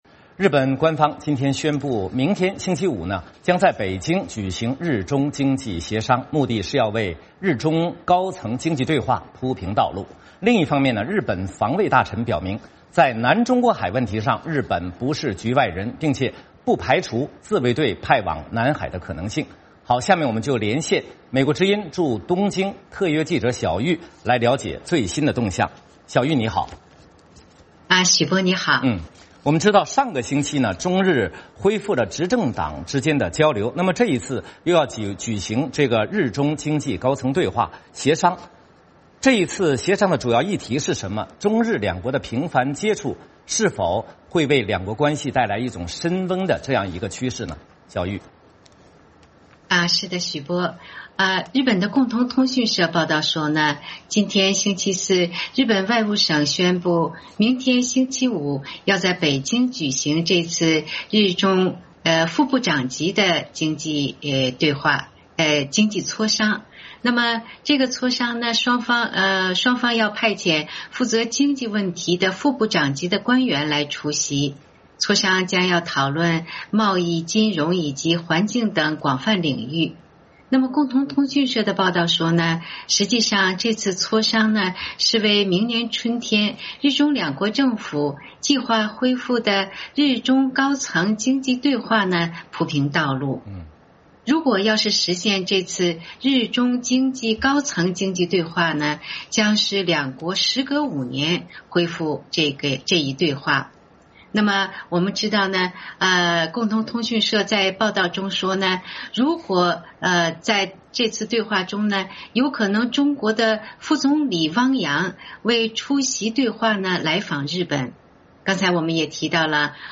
VOA连线：中日举行两国经济协商